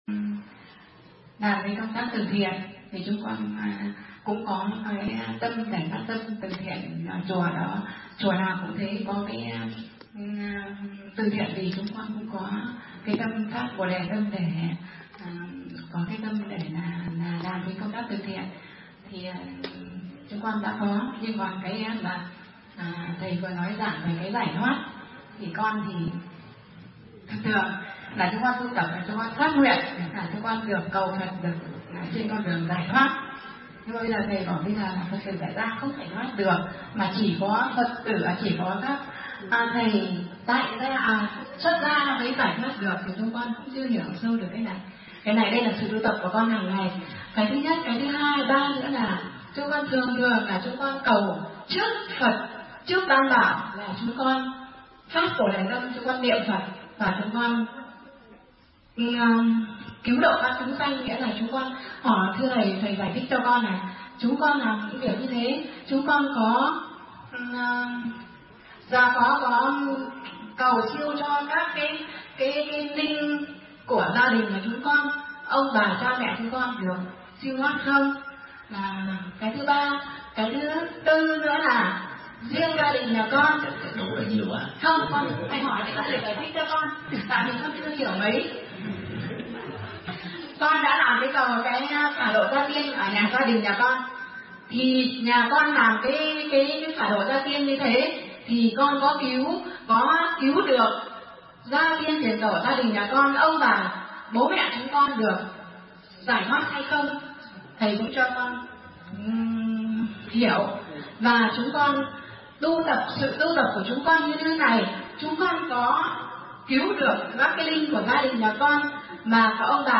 Vấn đáp: Giải thoát của người Phật tử, cầu siêu cho tổ tiên – Thích Nhật Từ